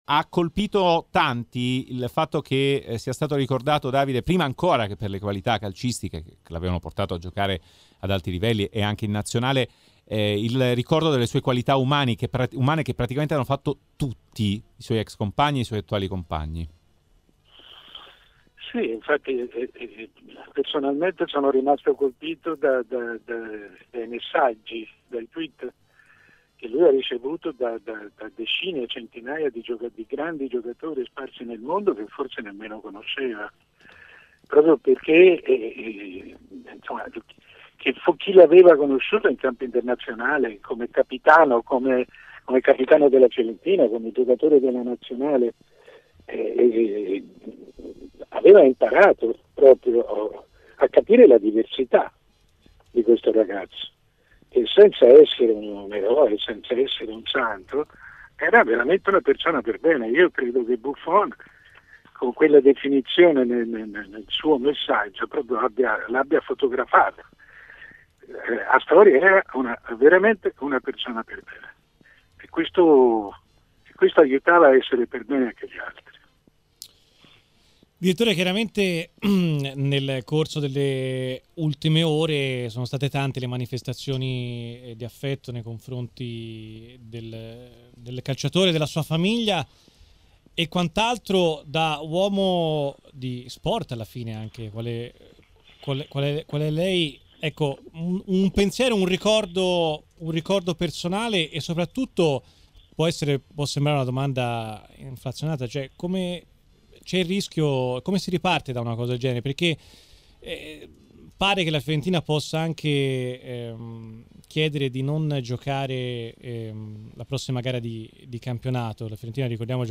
Mario Sconcerti, opinionista RMC Sport, sulla scomparsa di Davide Astori e sulle nuove date della Serie A.